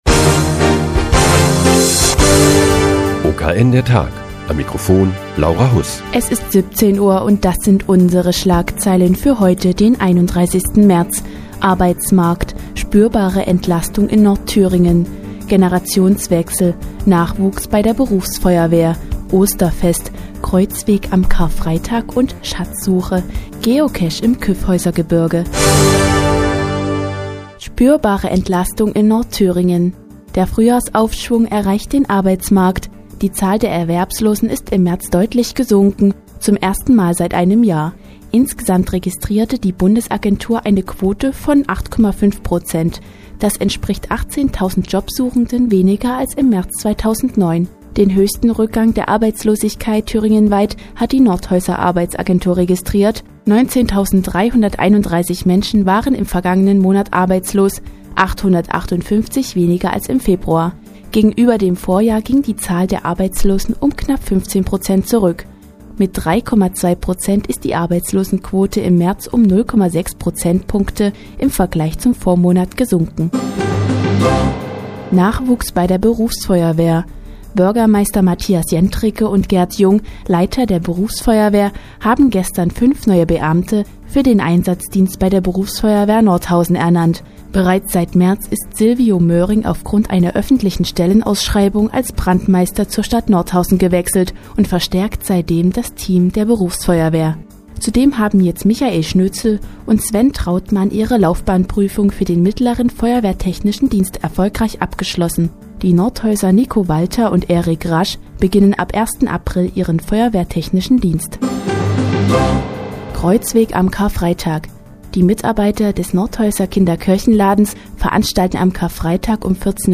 Die tägliche Nachrichtensendung des OKN ist nun auch in der nnz zu hören. Heute geht es um den Nachwuchs bei der Berufsfeuerwehr und ein spezielles Angebot für Kinder am Karfreitag.